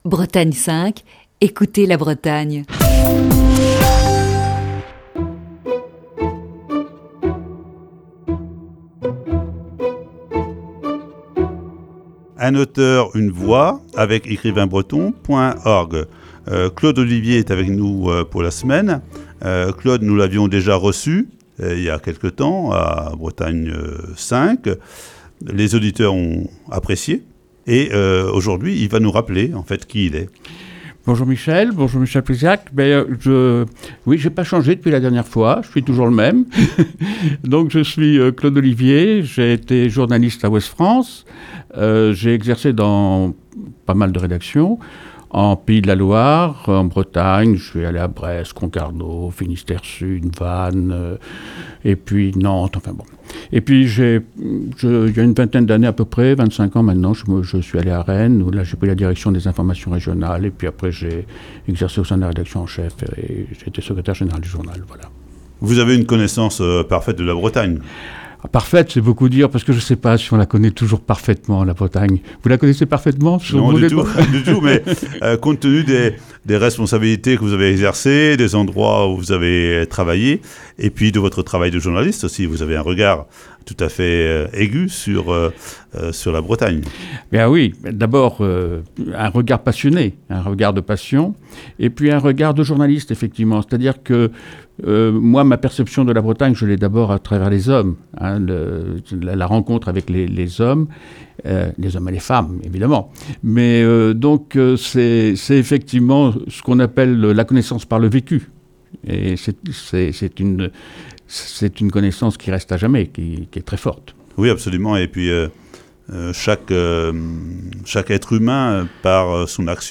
Voici ce lundi, la première partie de cet entretien diffusé le 2 mars 2020.